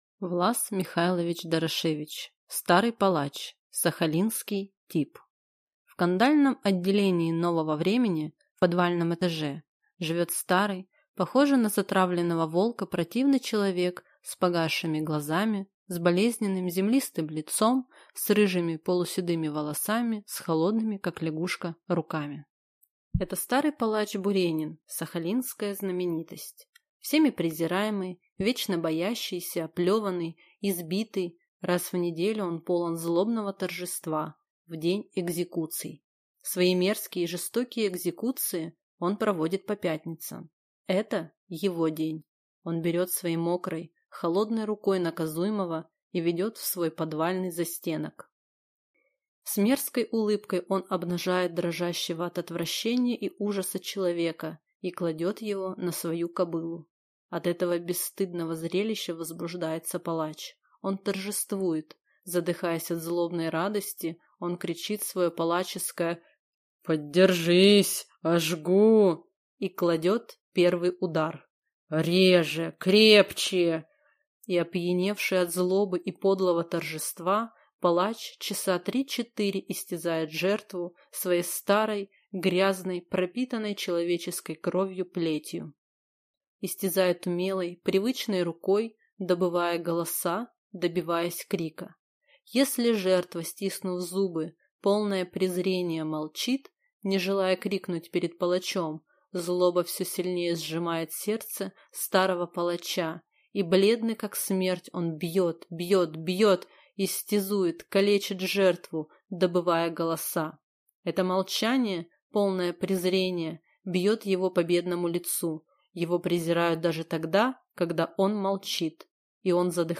Аудиокнига Старый палач | Библиотека аудиокниг